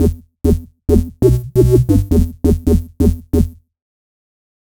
Bass Funk 2.wav